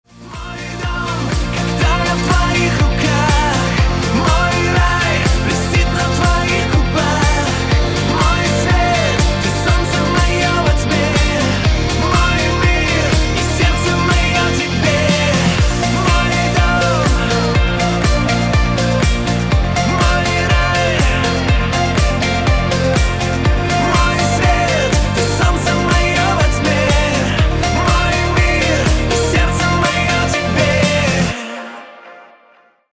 • Качество: 192, Stereo
поп
мужской вокал